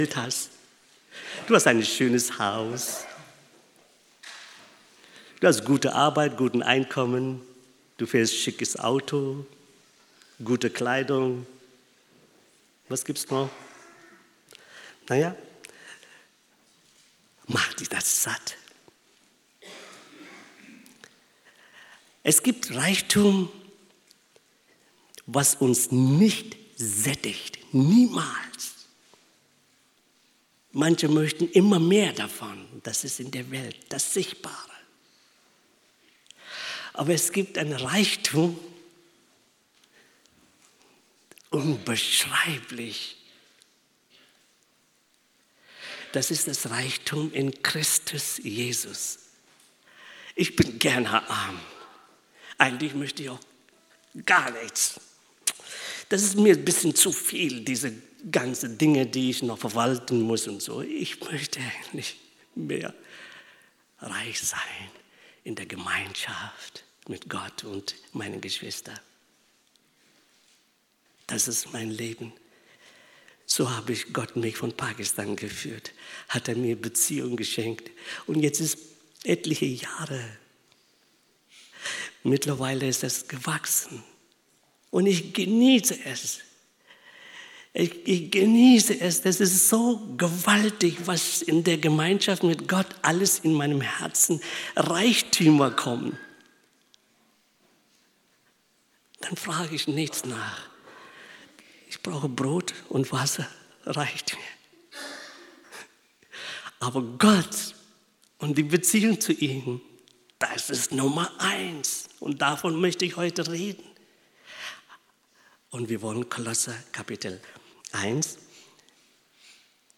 Predigten - KSS
Predigtarchiv